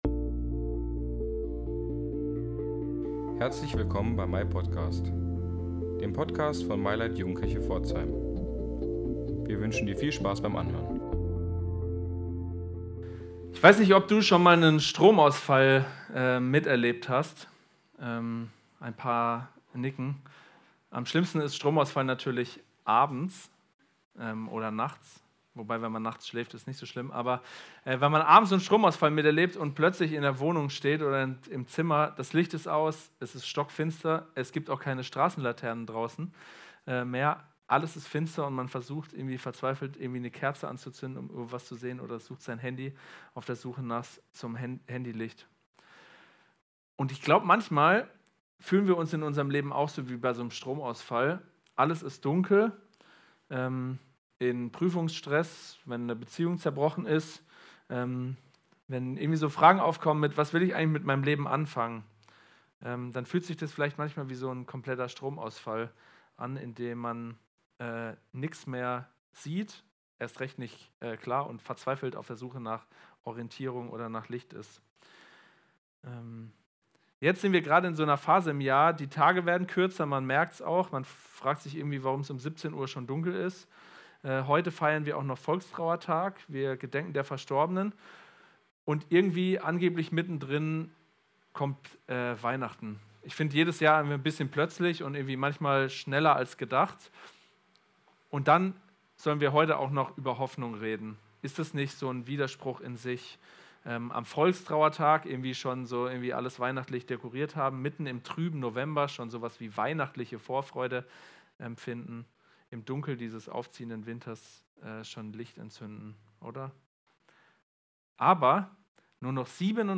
Gottesdienst im Wohnzimmer vom 17.